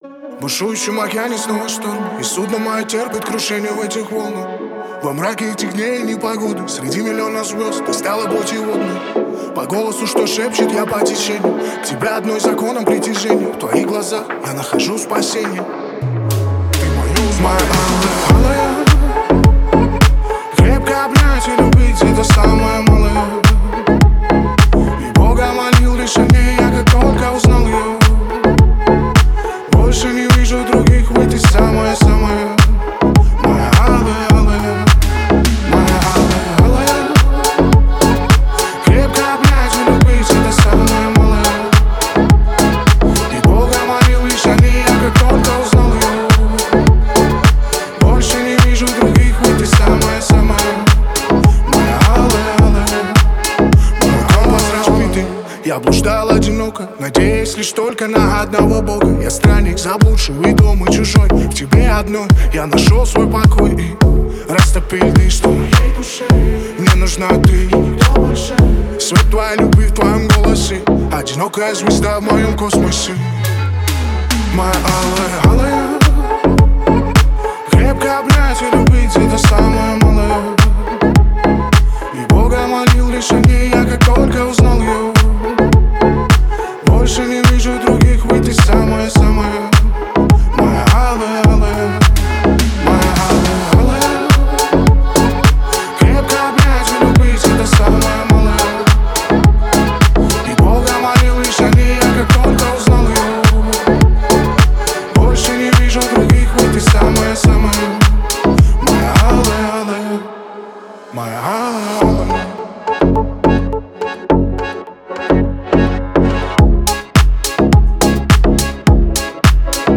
Deep House музыка
русский Deep House Размер файла